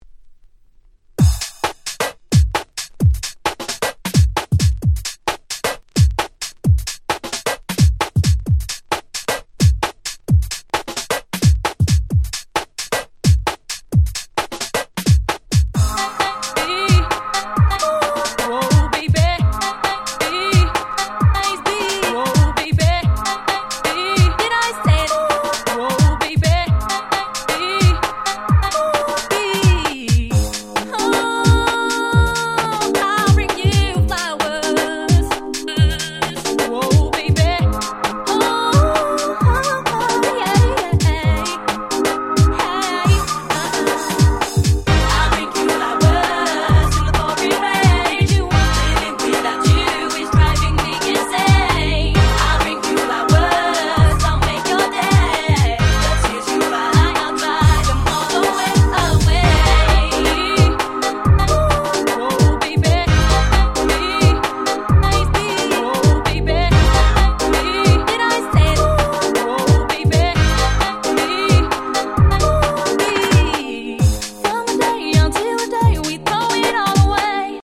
00' Super Hit 2 Step !!
今でもDance Musicとしてバッチリ機能する1枚です。